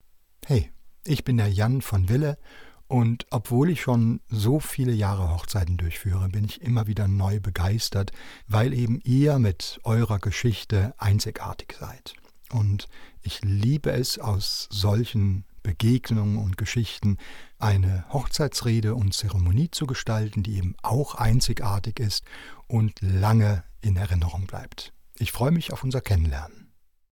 So klingt meine Stimme